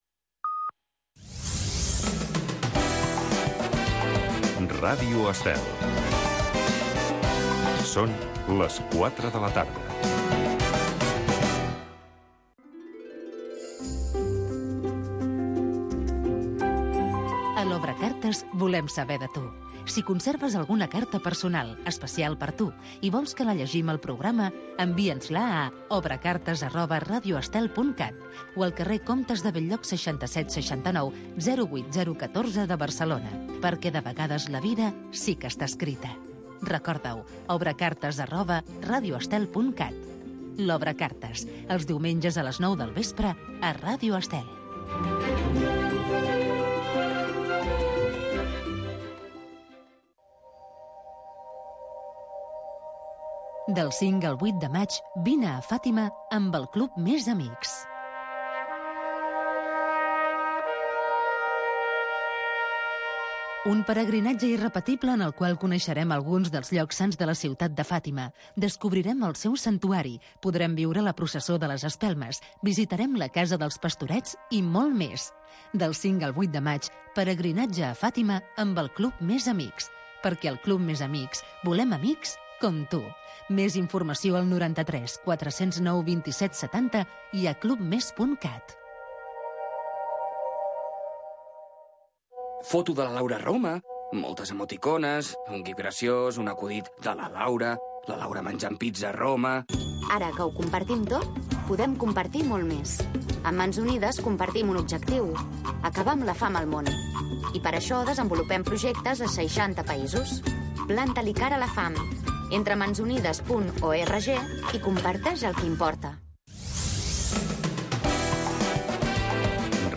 Fórmula musical de jazz